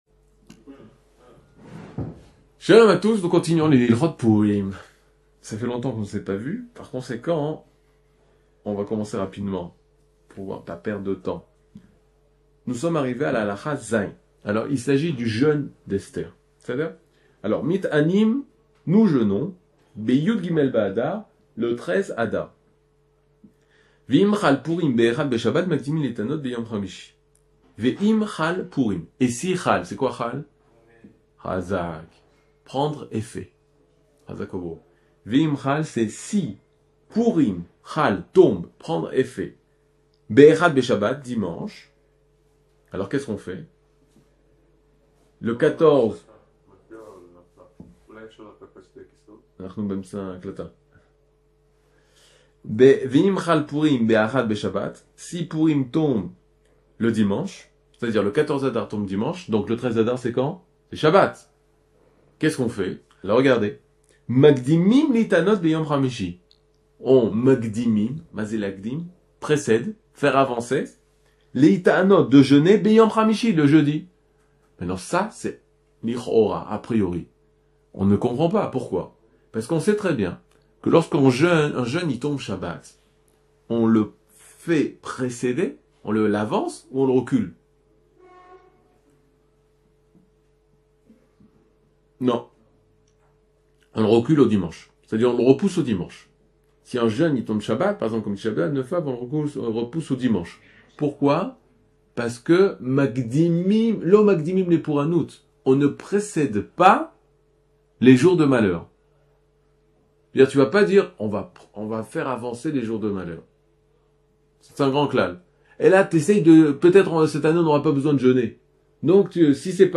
Le jeûne d'Esther, le jeûne de l'espoir. 00:10:10 Le jeûne d'Esther, le jeûne de l'espoir. cours du 06 mars 2023 10MIN Télécharger AUDIO MP3 (9.29 Mo) Télécharger VIDEO MP4 (23.19 Mo) TAGS : Mini-cours